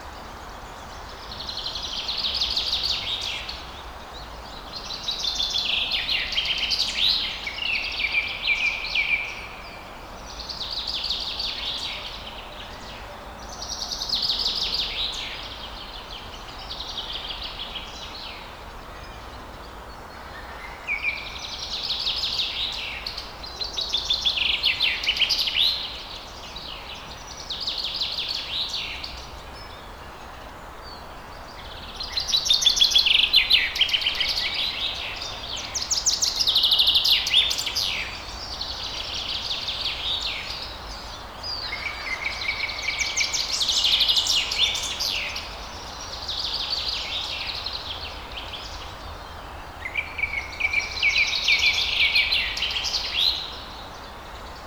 birds-in-forest-loop.wav